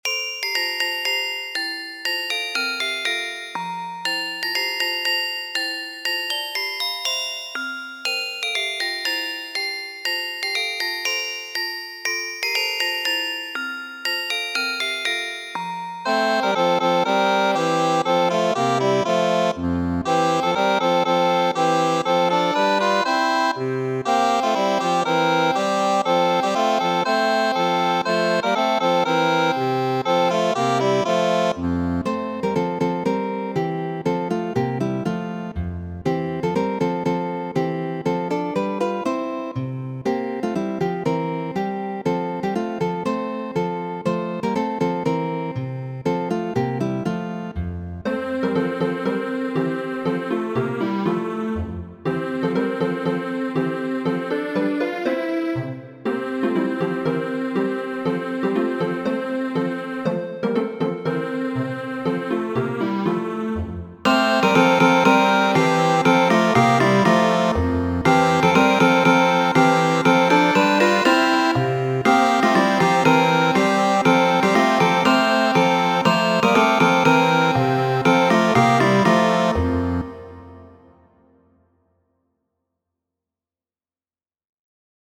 Muziko : Blühe, liebes Veilchen (florado, mia kara violo), germana popolkanto.